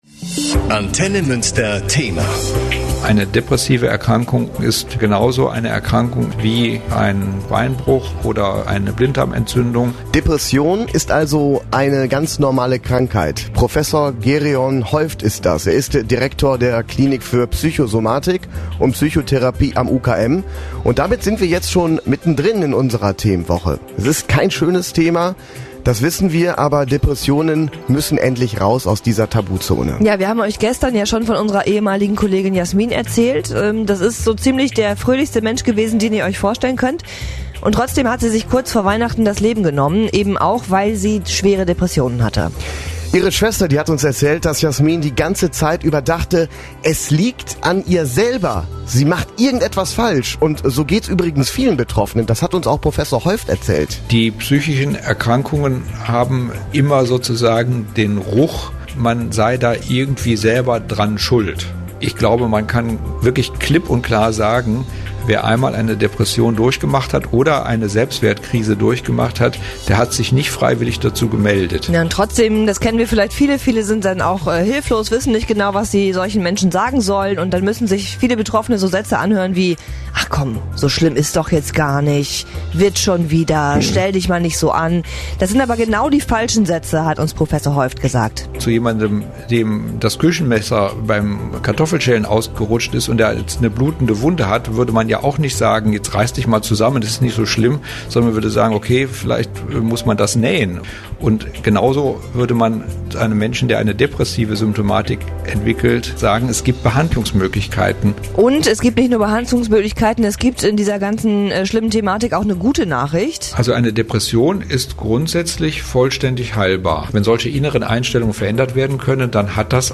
depressionen---was-der-experte-sagt.mp3